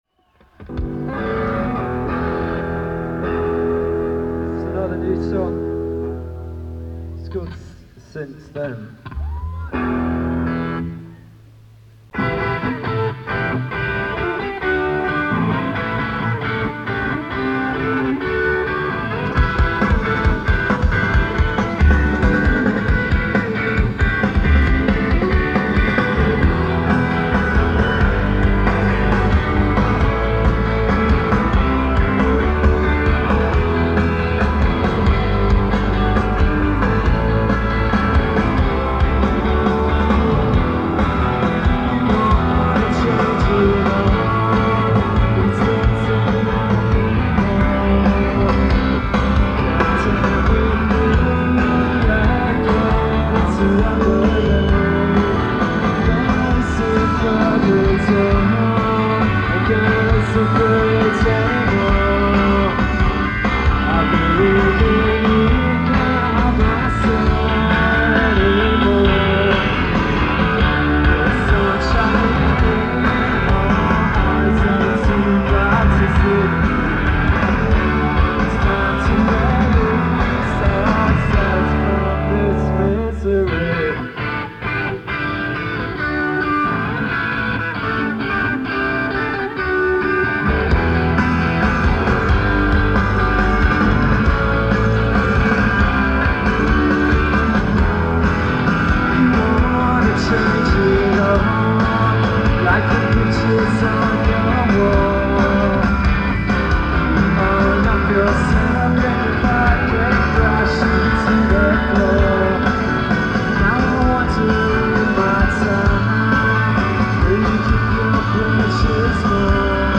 Live at the Centre, Brighton, UK